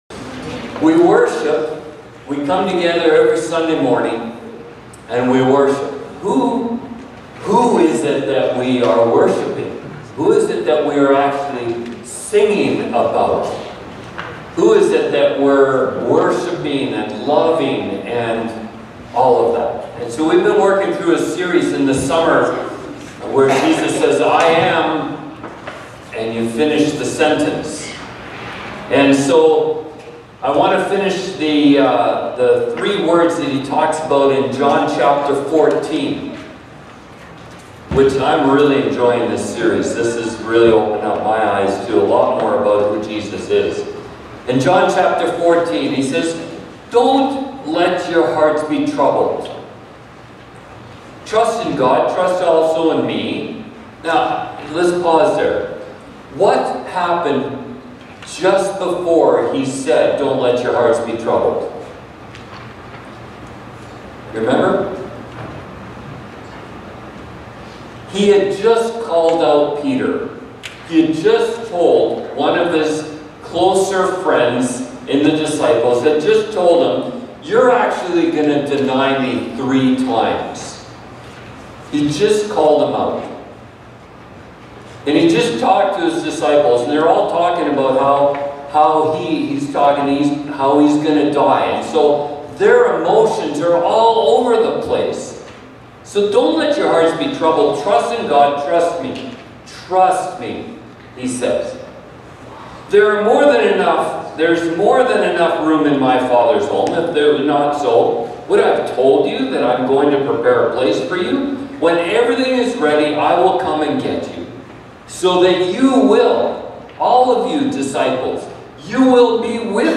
Sermons | Rosenort Community Church